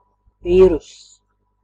Ääntäminen
IPA : [ˈvaɪ.rəs]